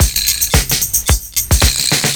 112PERCS06.wav